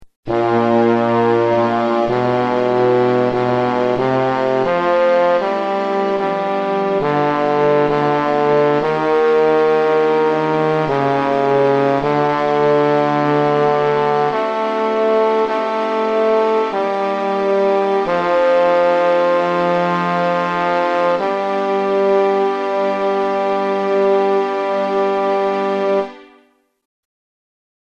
Key written in: G Minor
Type: Other male